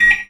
beep_10.wav